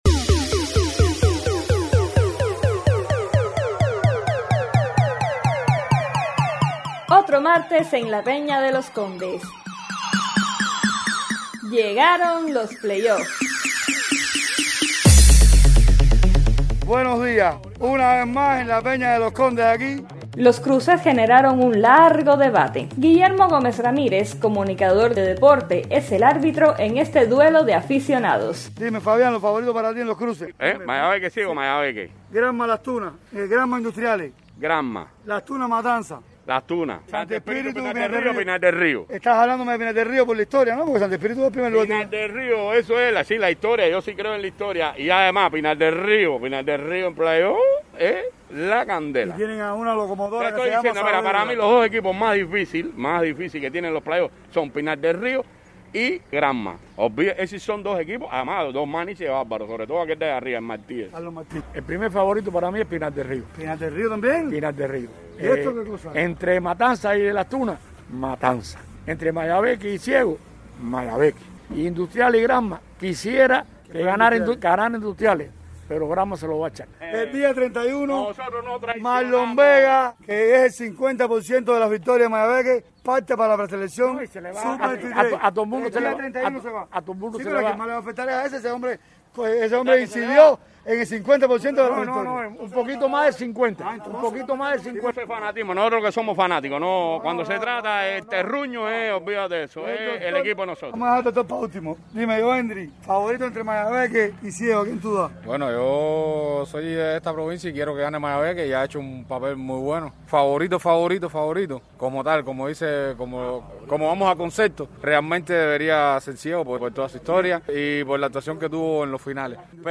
Noticias deportivas